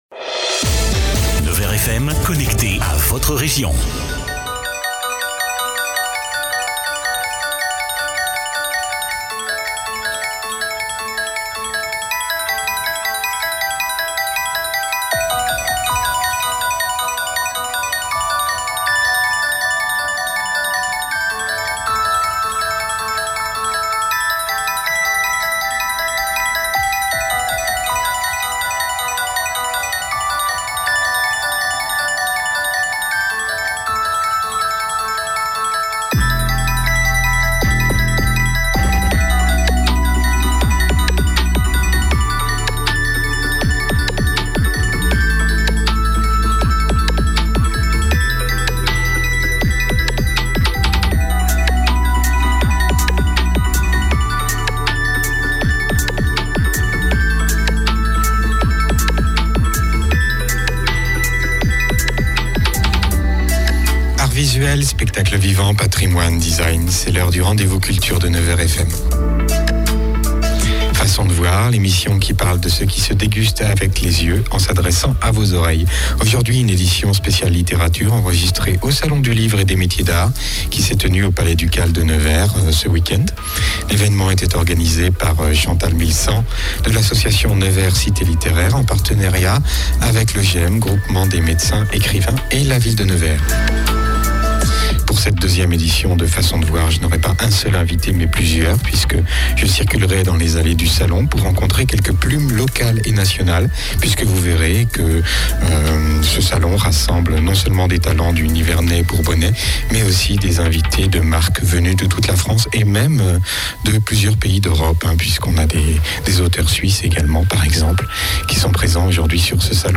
3549 vues Écouter le podcast Télécharger le podcast Facebook de l'émission Mercredi 7 Juin, une édition spéciale littérature enregistrée le dimanche 4 juin au Salon du livre et des métiers d'art au Palais Ducal de Nevers.